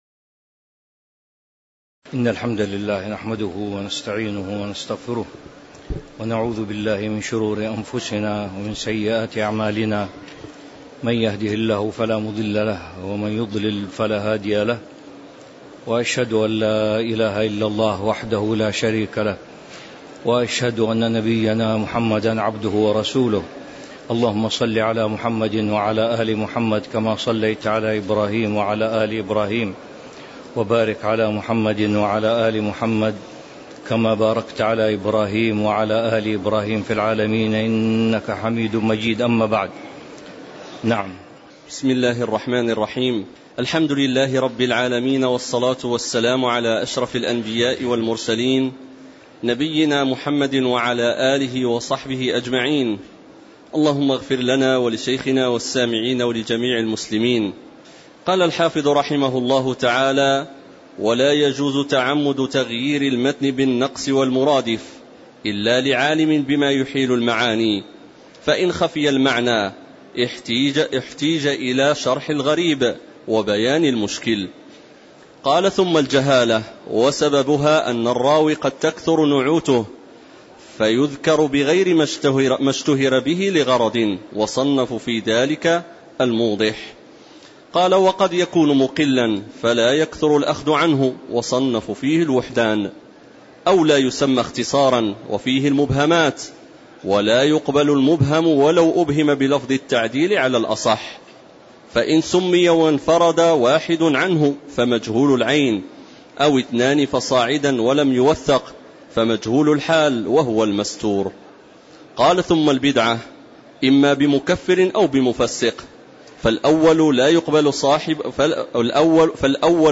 تاريخ النشر ١٣ جمادى الآخرة ١٤٤٤ هـ المكان: المسجد النبوي الشيخ